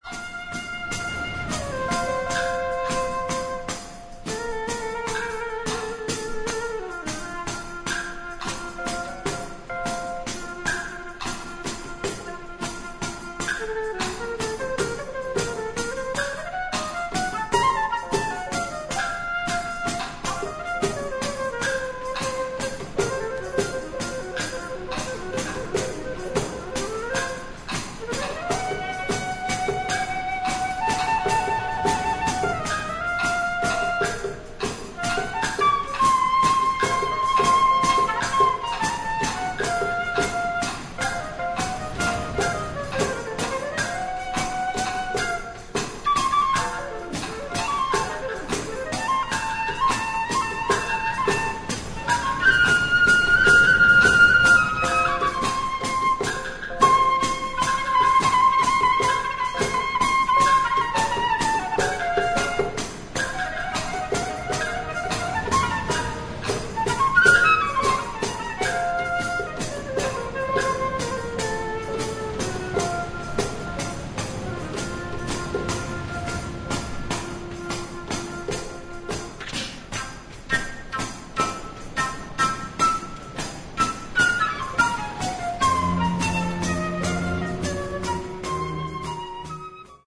本作は、フェロー諸島にある断崖の渓谷の洞窟でレコーディングされたライヴ音源。